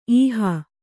♪ īha